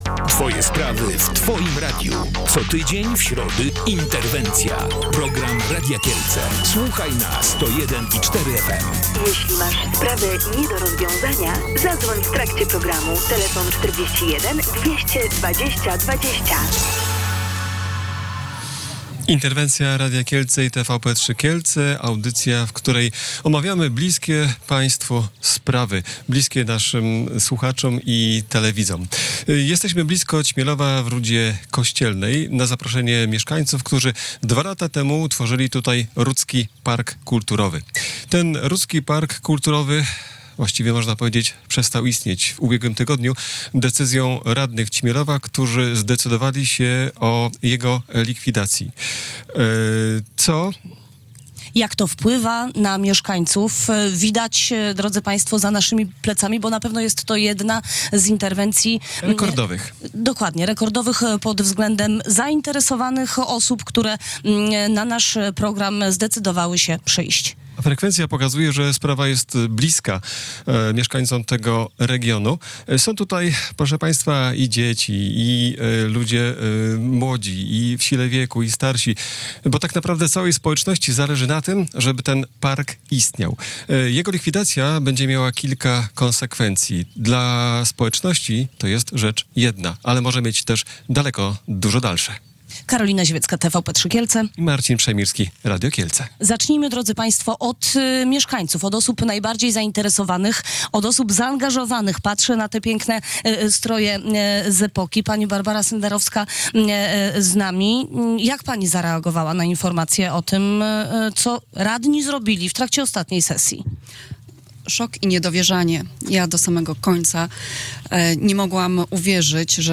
Na dzisiejszy (środa, 26 marca) program Interwencja przybyło około 200 mieszkańców okolicznych miejscowości, członkowie stowarzyszeń i wszyscy zaangażowani w istnienie Rudzkiego Parku Kulturowego oraz zainteresowani tym, by Krzemionki Opatowskie mogły nadal być na liście światowego dziedzictwa UNESCO.